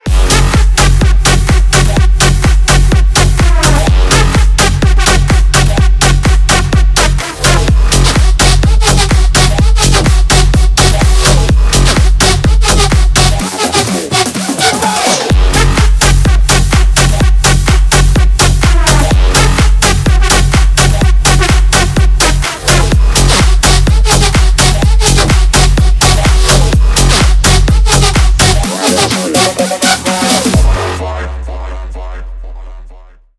Электроника
клубные # громкие # без слов